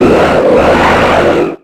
Cri de Tropius dans Pokémon X et Y.